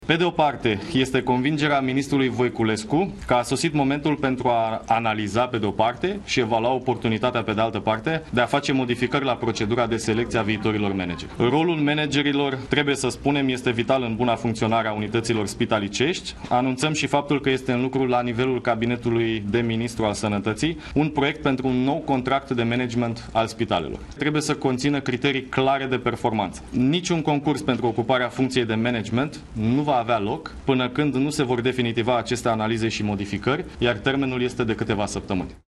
a declarat în cadrul unei conferințe de presă